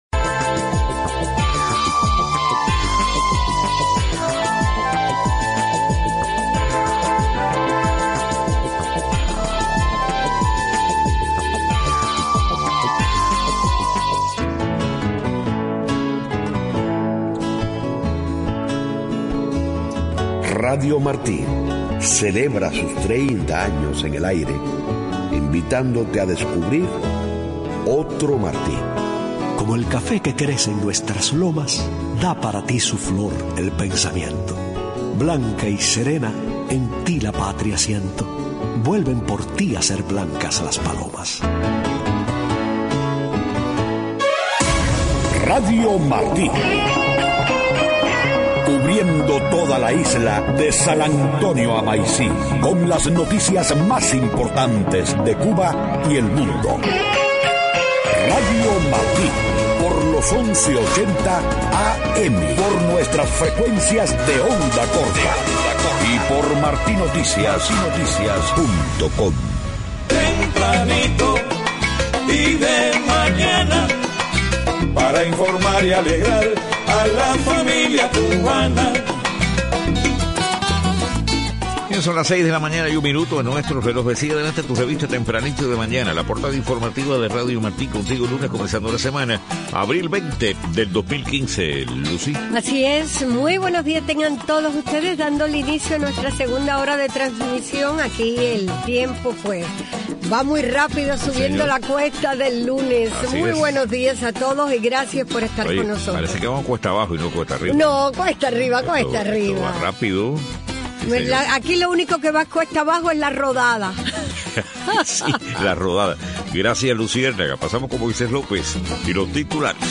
6:00 a.m. Noticias: Por primera vez participaron 2 opositores en las elecciones municipales en Cuba, pero no lograron escaños. FMI hace seguimiento a las negociaciones entre Cuba y EEUU.